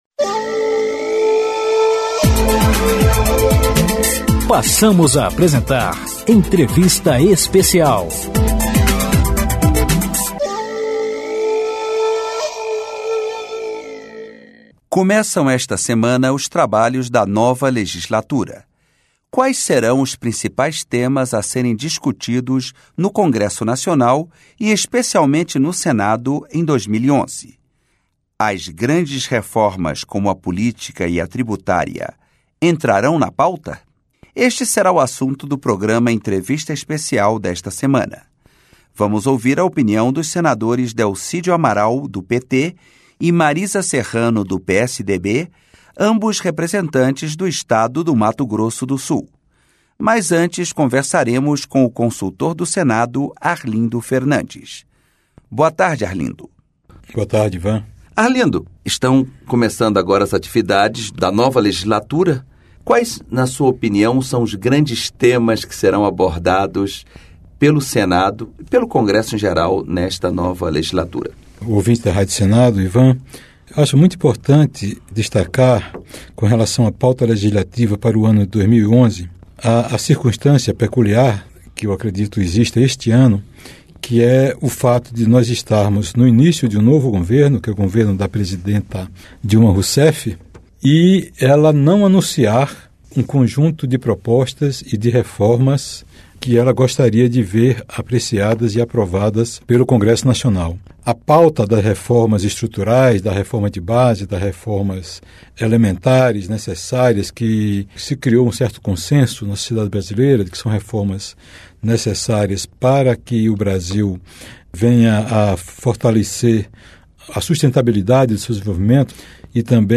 Entrevista Especial